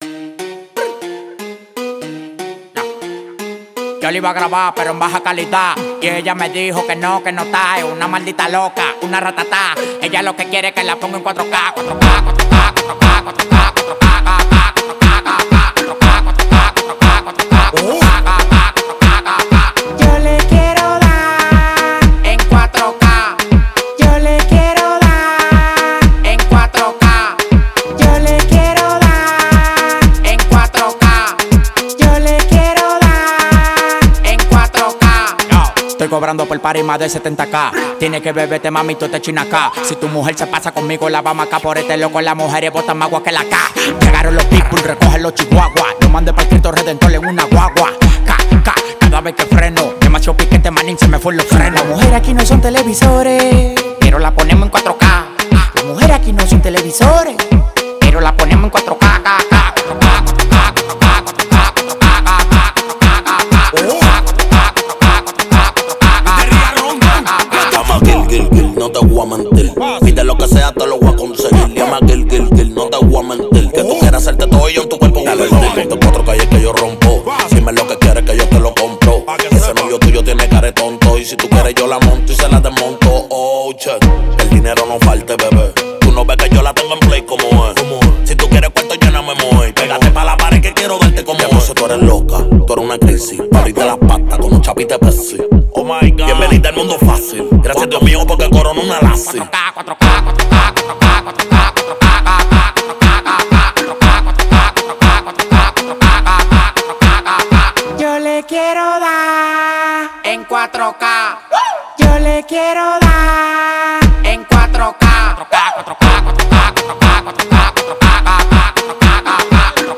خارجی شاد و بیس دار توپ
مخصوص رقص باشگاه و سیستم بیس ماشین
آهنگ خارجی بیس دار